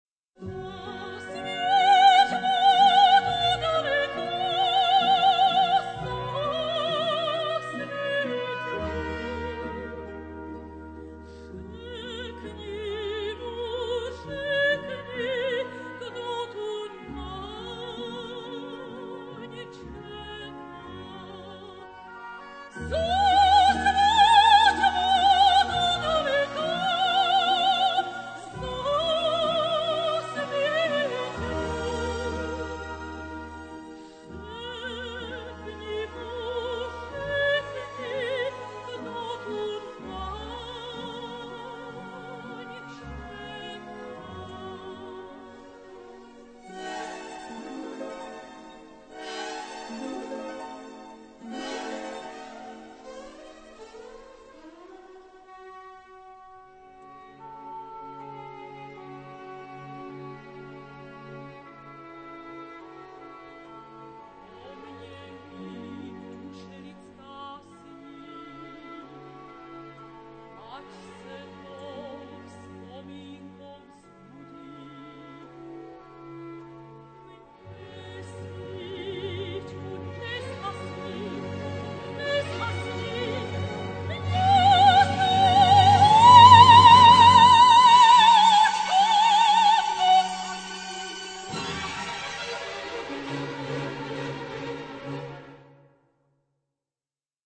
It isn't easy, for example, to put together a Czech-speaking cast for the marvelous opera Rusalka. Listen to a portion of 'Song to the Moon' to see if you are a potential convert [